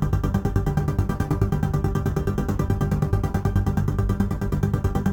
Index of /musicradar/dystopian-drone-samples/Tempo Loops/140bpm
DD_TempoDroneC_140-G.wav